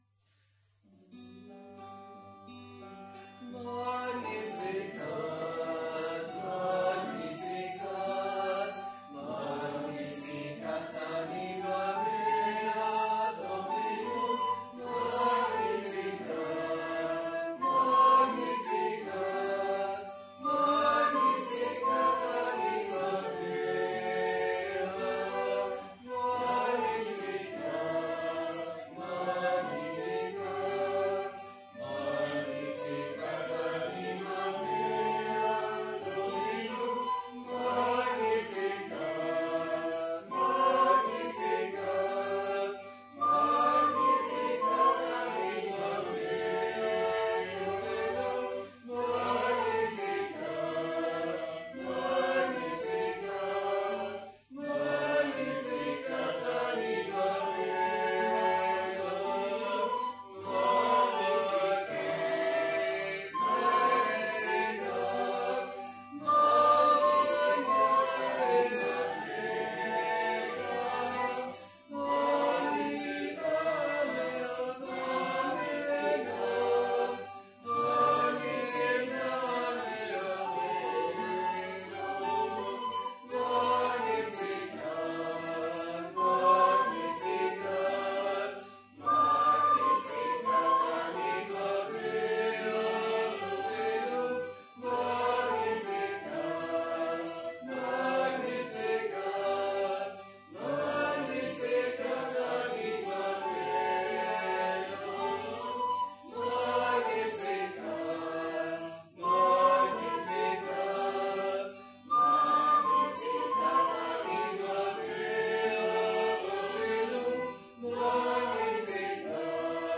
Capella de les Concepcionistes - Diumenge 27 de novembre de 2022
Vàrem cantar...